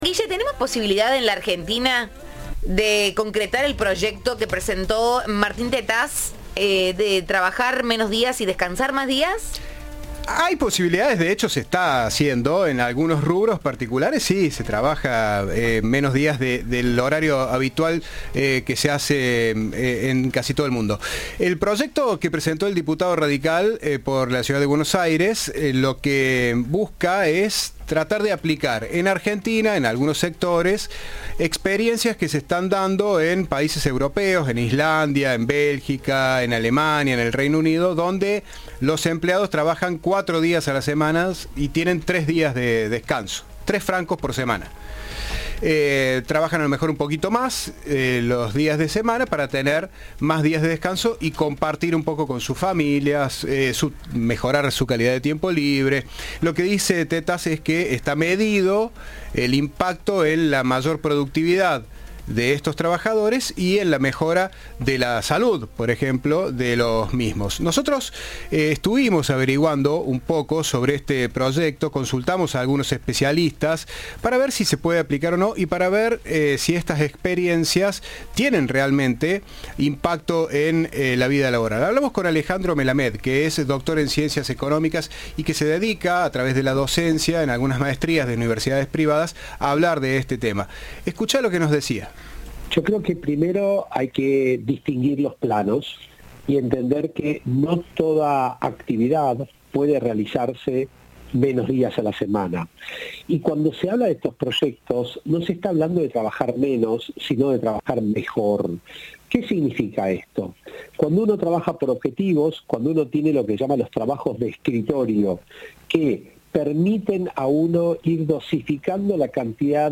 Doctor en Ciencias Económicas y docente universitario especializado en la temática.